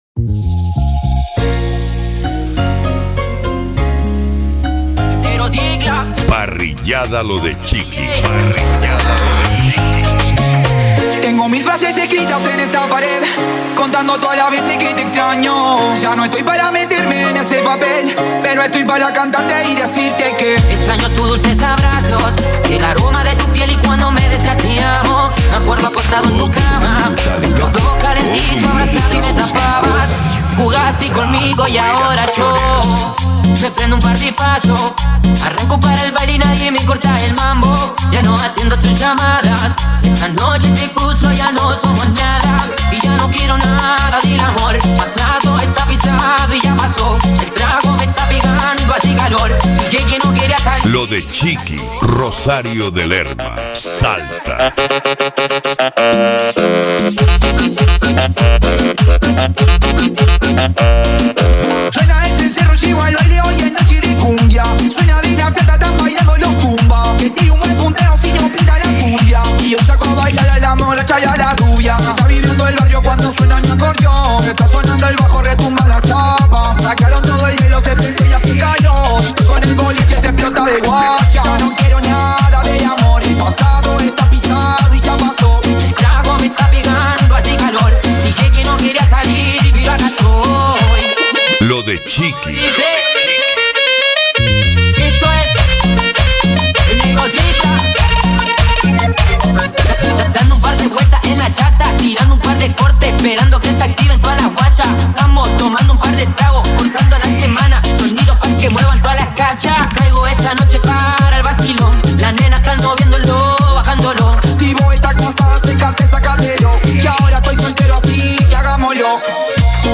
Remix
Retro Music